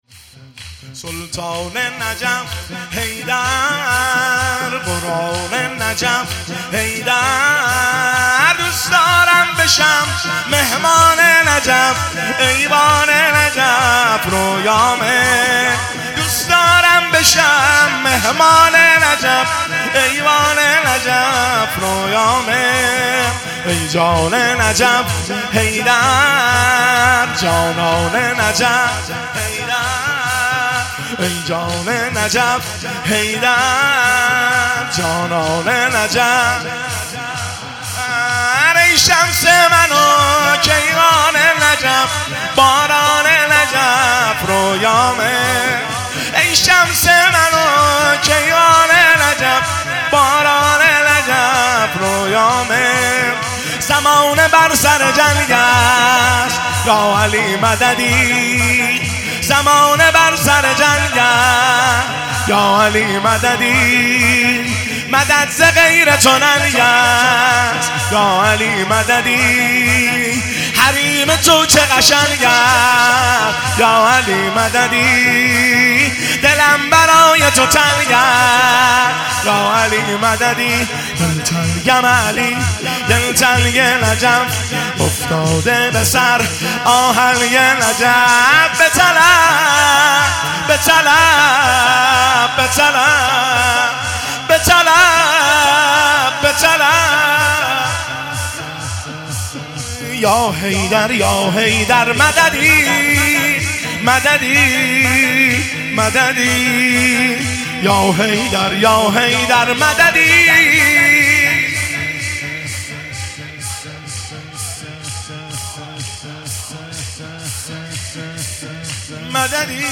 تک شب هشتم محرم الحرام 1403
دانلود سبک تک